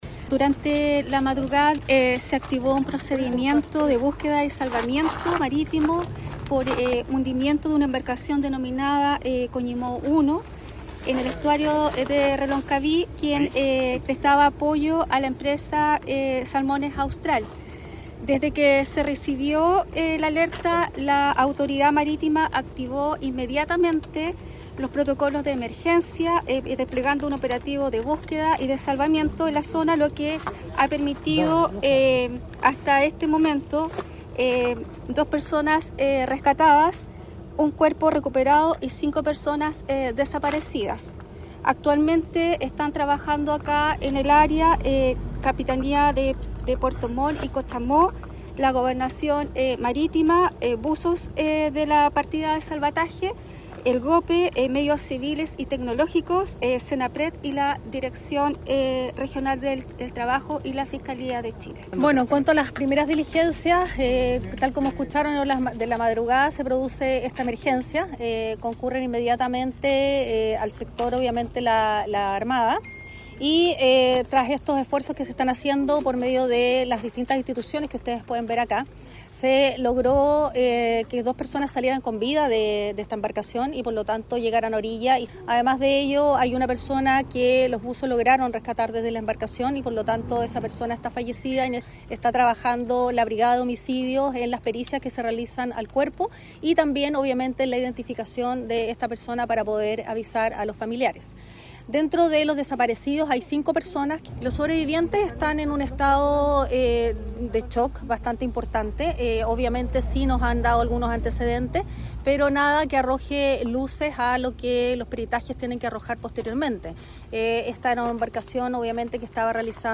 Información que entregó la delegada presidencial regional (S) Claudia Pailalef y la Fiscal Regional (S), María Angélica de Miguel.
DELEGADA-FISCAL-REGIONAL.mp3